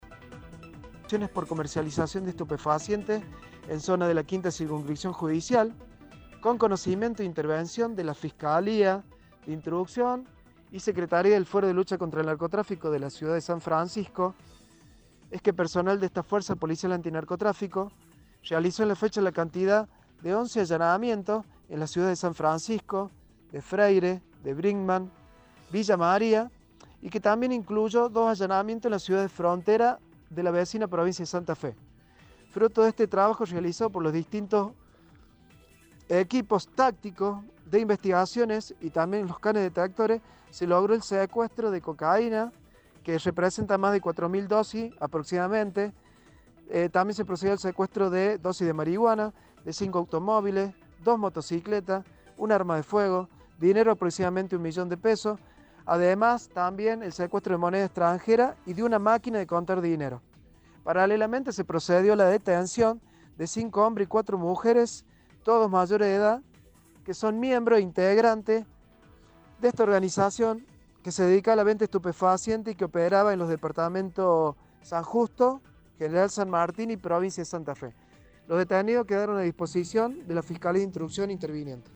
AUDIO: Lic. Mario Simbron – Director General de Investigaciones de la Fuerza Policial Antinarcotráfico